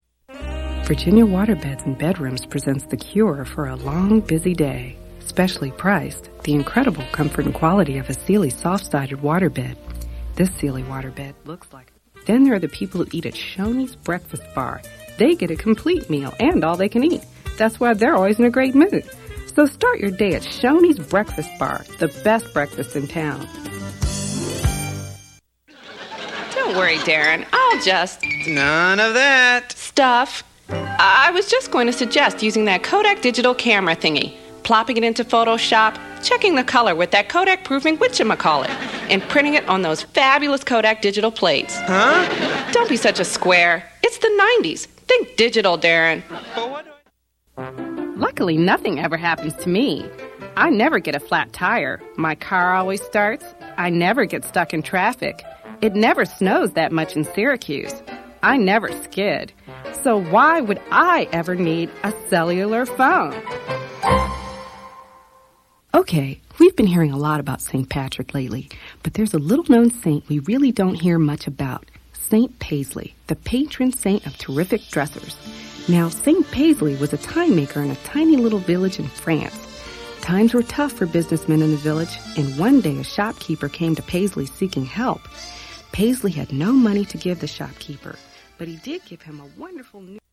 commercials.mp3